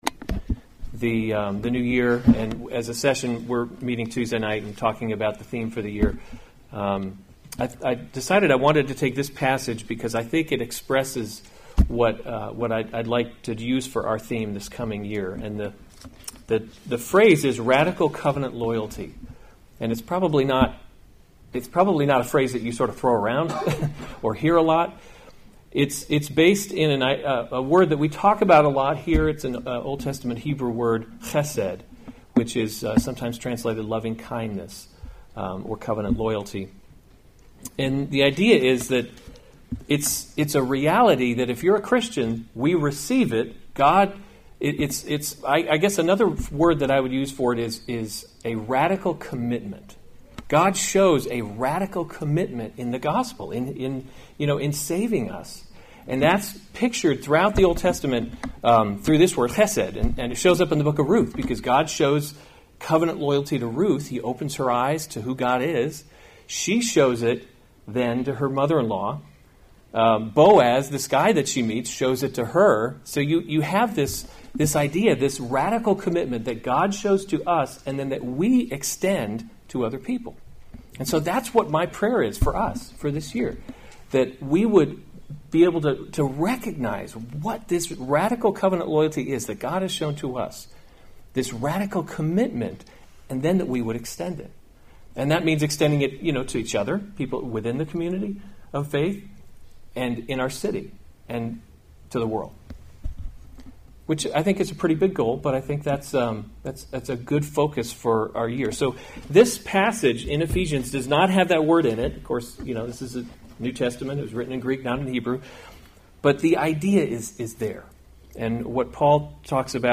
January 5, 2019 Special Services series Weekly Sunday Service Save/Download this sermon Ephesians 2:19-22 Other sermons from Ephesians 19 So then you are no longer strangers and aliens, [1] but you are […]